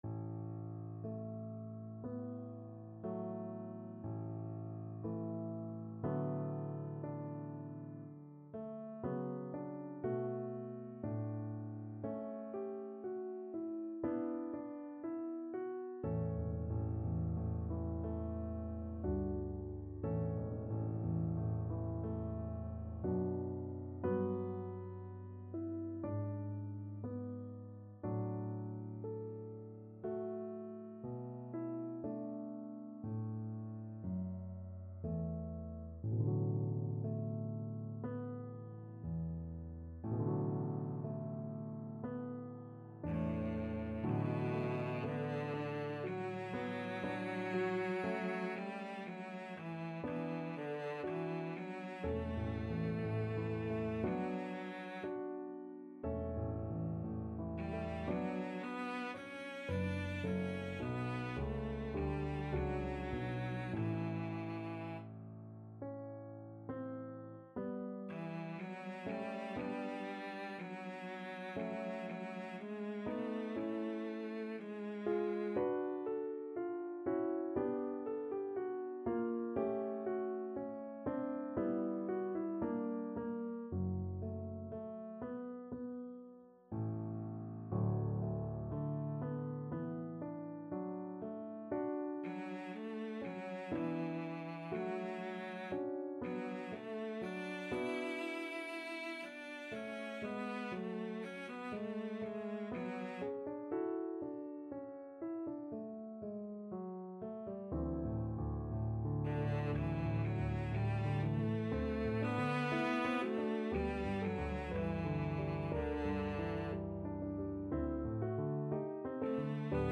= 60 Molto lento e ritenuto
4/4 (View more 4/4 Music)
Classical (View more Classical Cello Music)